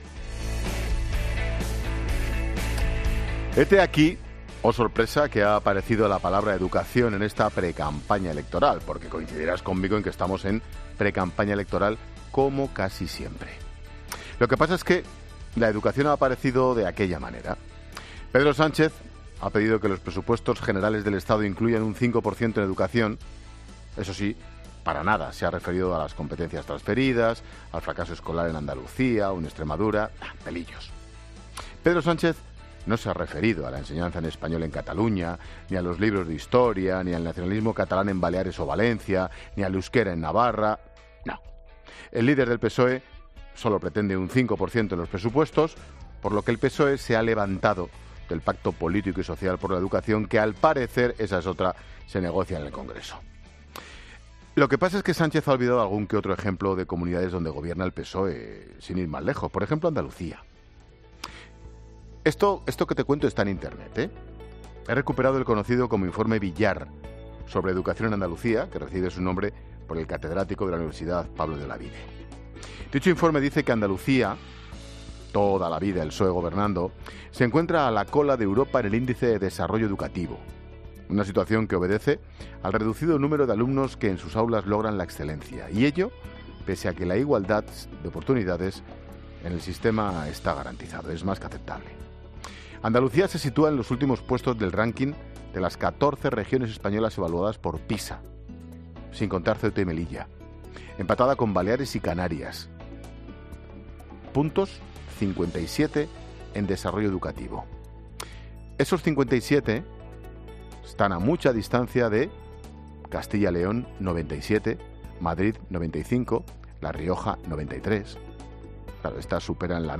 AUDIO: El comentario de Ángel Expósito.